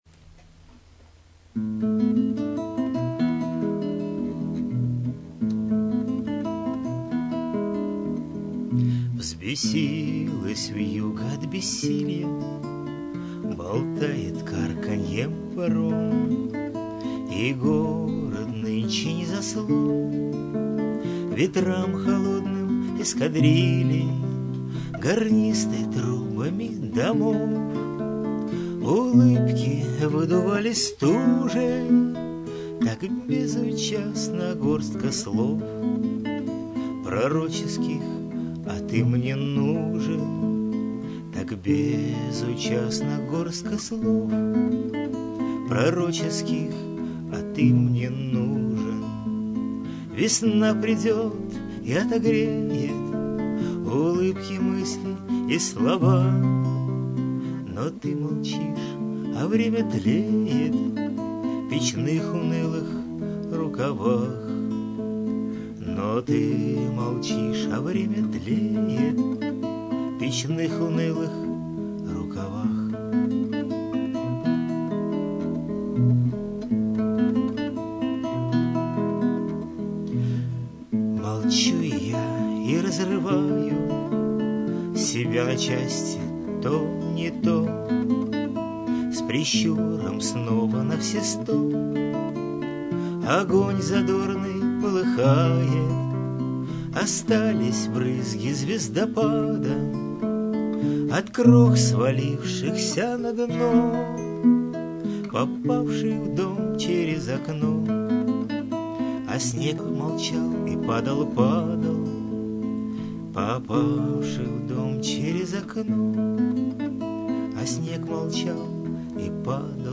Предвесеннее (Darida, романс)